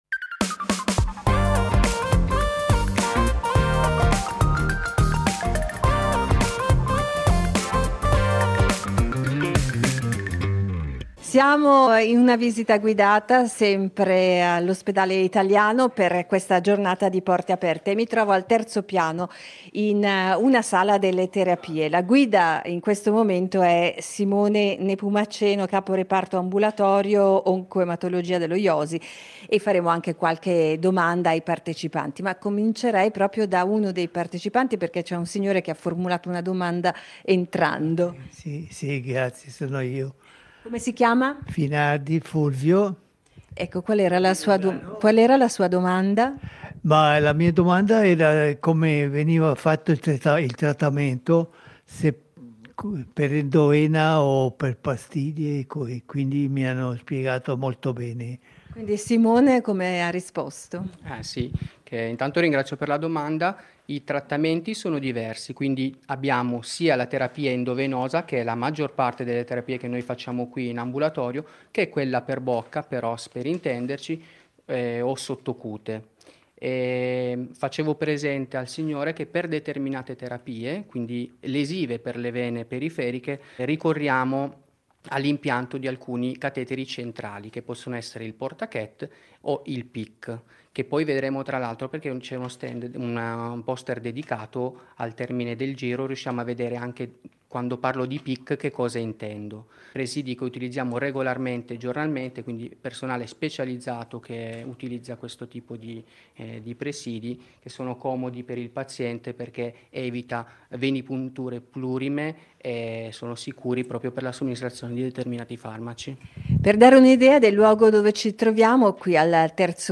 In diretta dall’Ospedale Italiano di Lugano